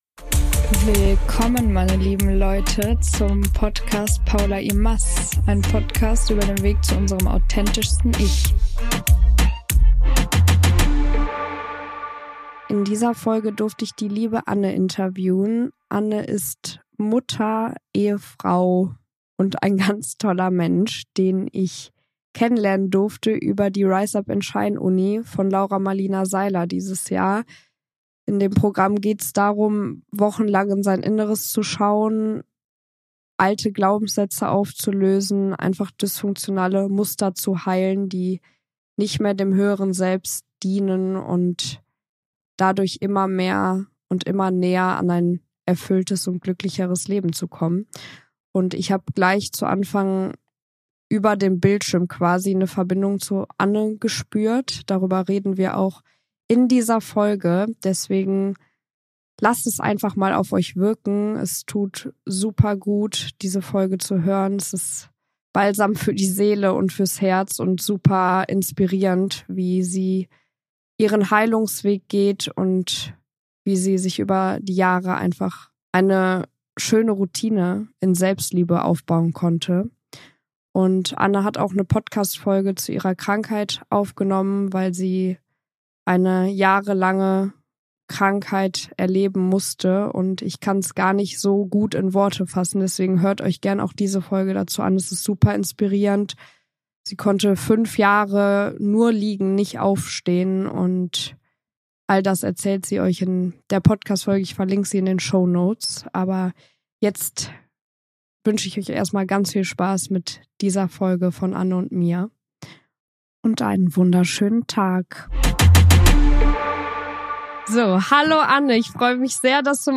#33 - Wie wir die Angst loslassen, unser wahres Ich zu zeigen - Interview